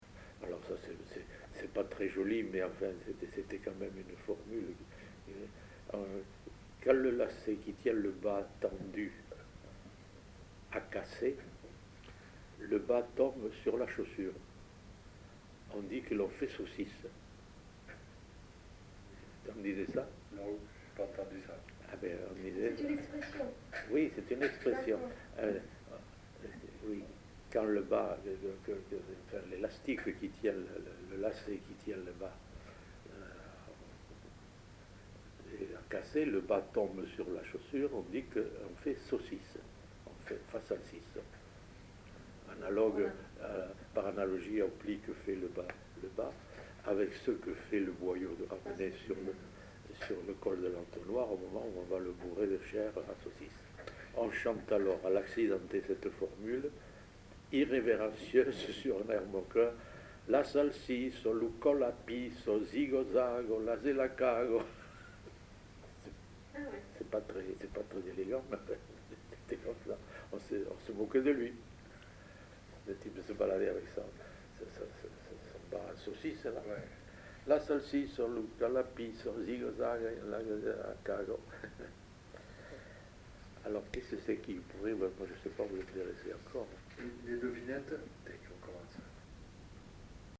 Lieu : Saint-Sauveur
Genre : forme brève
Type de voix : voix d'homme
Production du son : récité
Classification : formulette